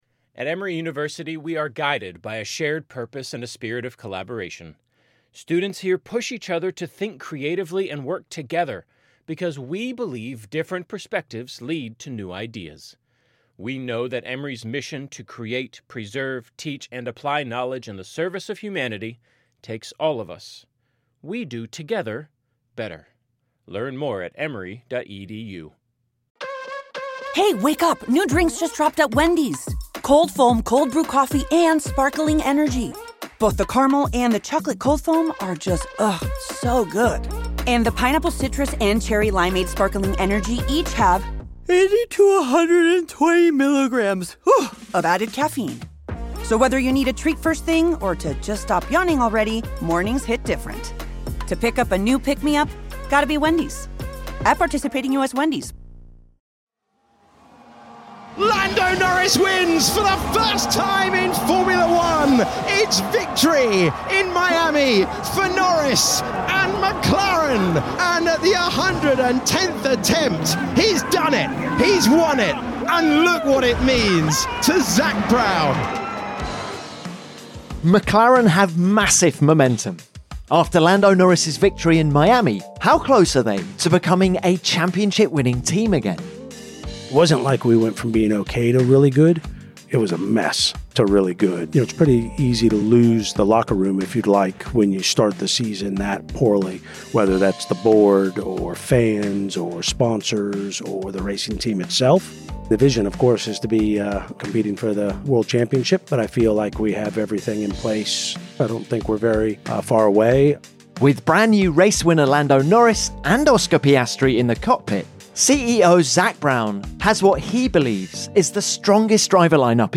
Speaking in Miami